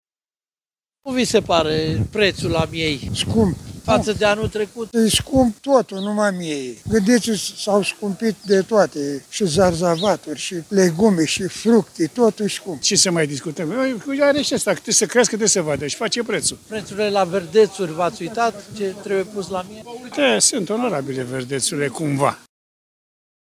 Brașovenii spun, însă, că prețurile sunt prea mari.